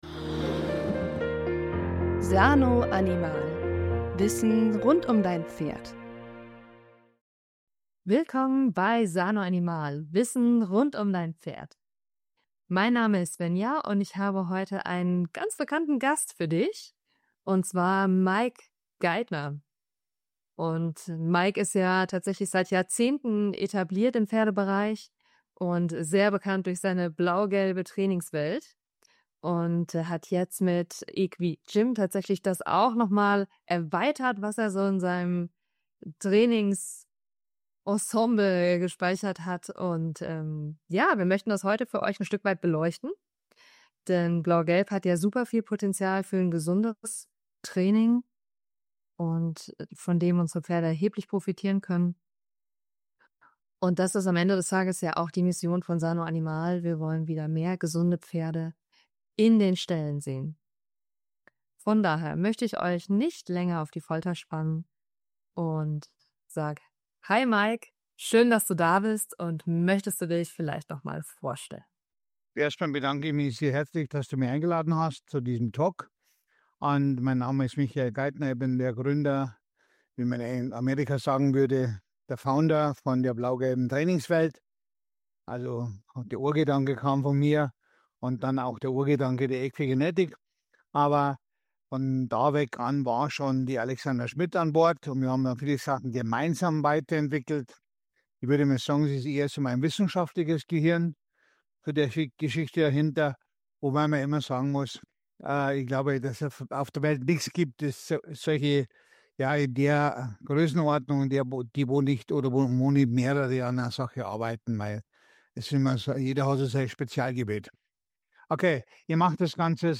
Sanoanimal präsentiert ein Gespräch